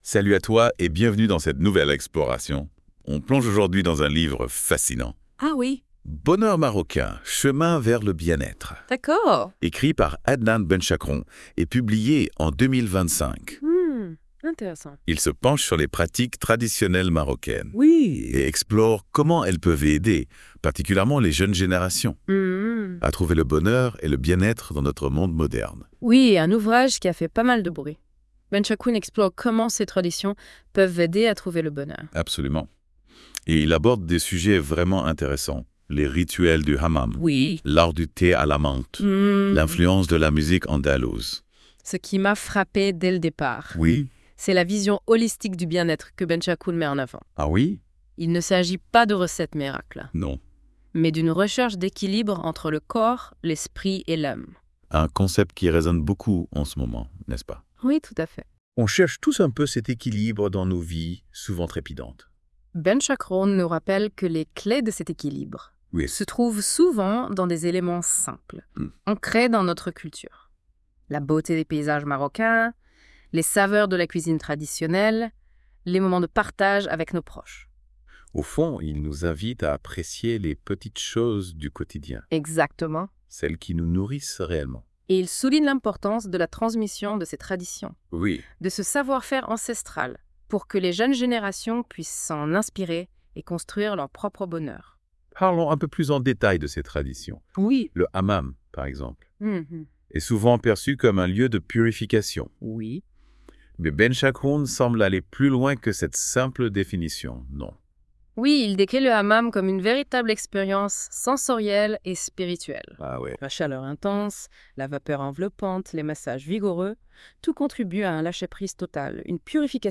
En attendant sa publication le 6 Janvier 2025, les chroniqueurs de la Web Radio R212 l'ont lu et en débattent dans ce podcast à travers ses questions :